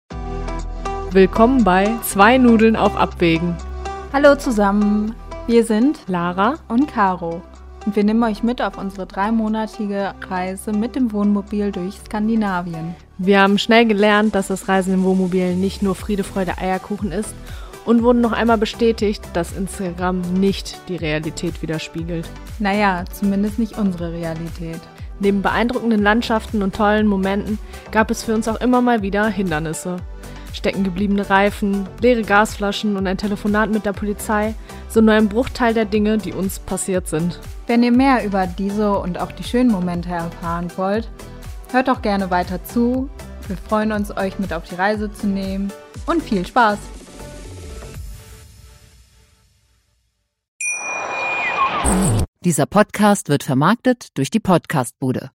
Zwei junge Frauen, ein Wohnmobil und eine unvergessliche Reise durch Skandinavien! 🚐💨